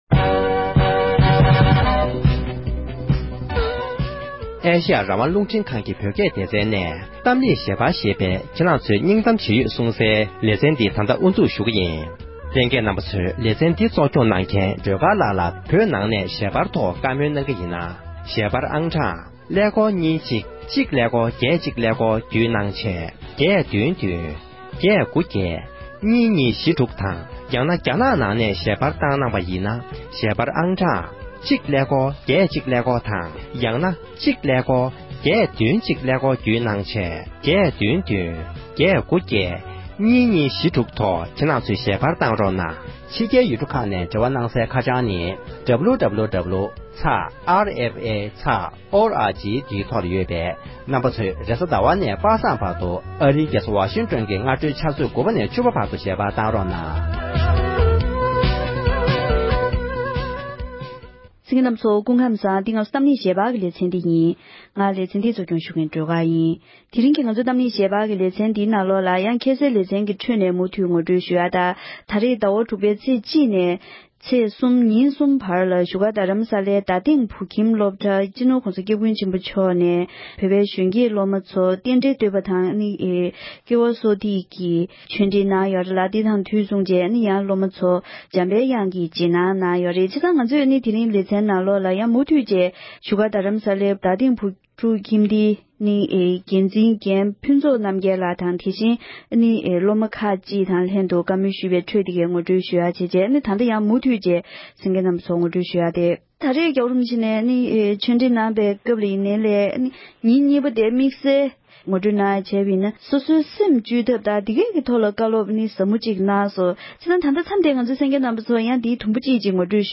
འབྲེལ་ཡོད་མི་སྣར་བཀའ་འདྲི་ཞུས་པའི་དུམ་བུ་བཞི་པ་འདིར་གསན་རོགས་ཞུ༎